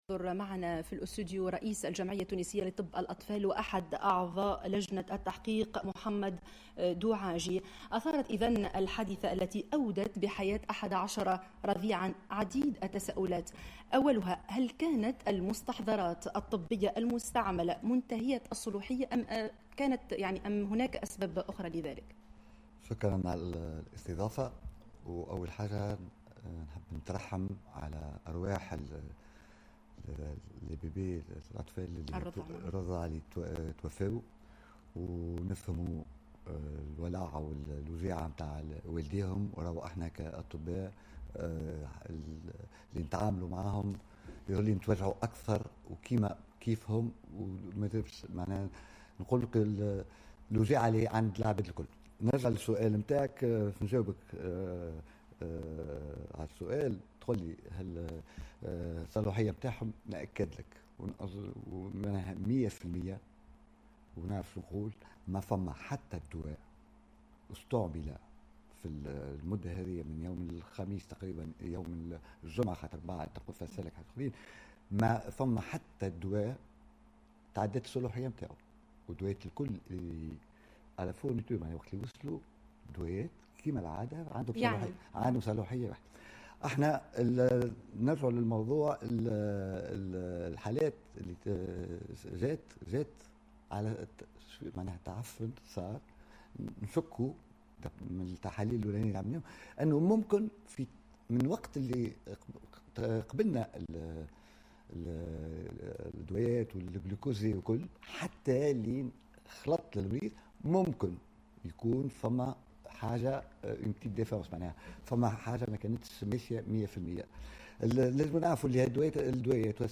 في مداخلة له على القناة الوطنية الأولى مساء اليوم